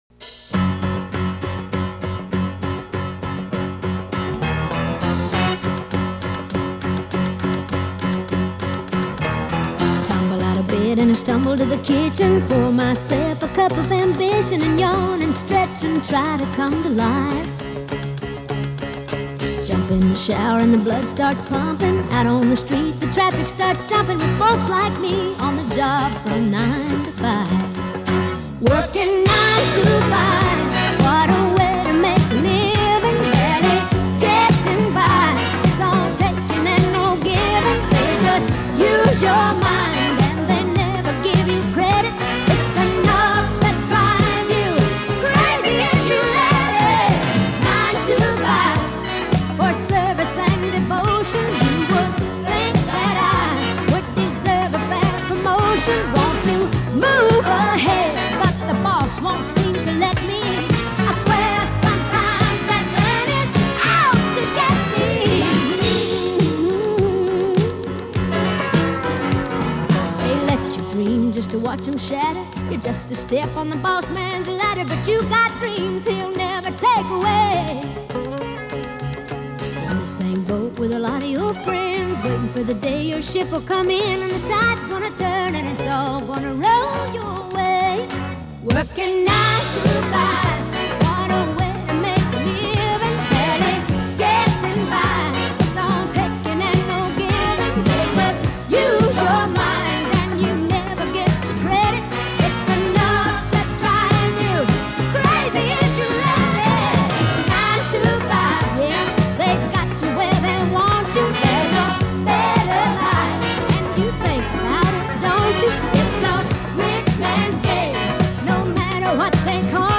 Country, Pop